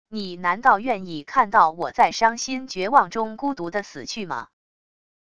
你难道愿意看到我在伤心绝望中孤独地死去吗wav音频生成系统WAV Audio Player